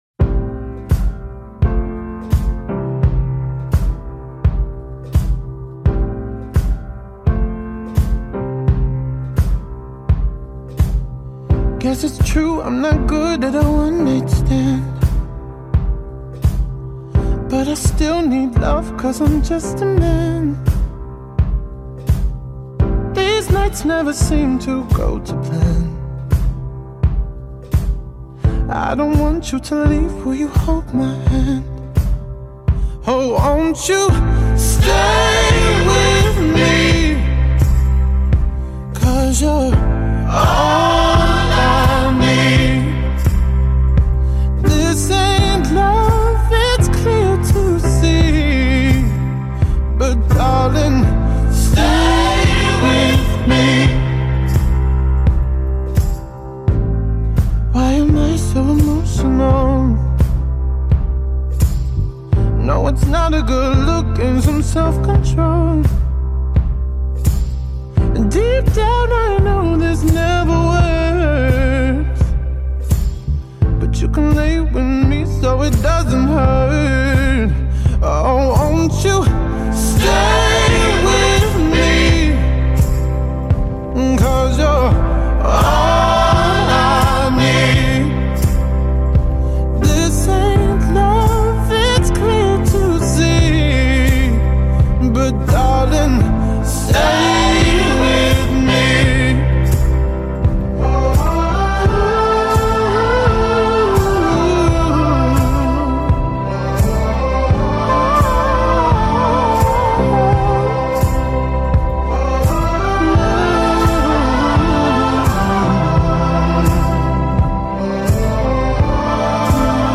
ballada popowa z 2014 roku